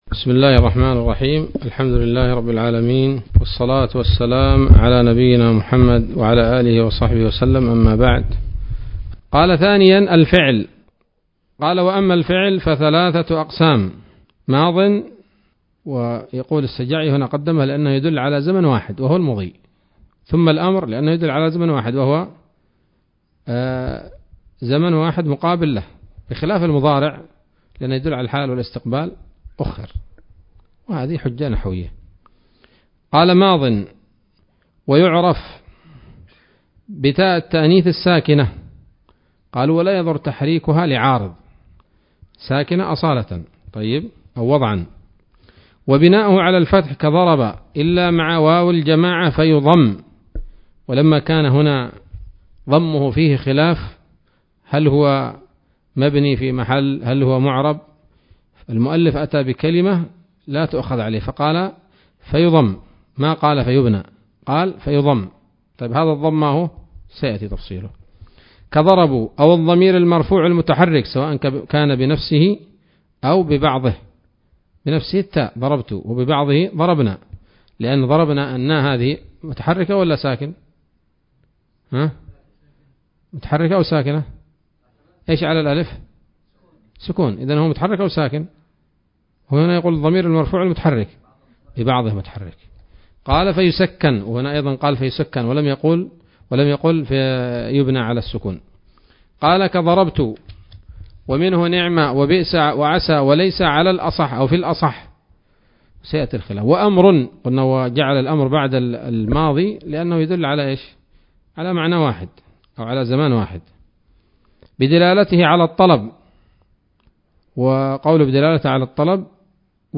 الدرس الثامن من شرح قطر الندى وبل الصدى [1444هـ]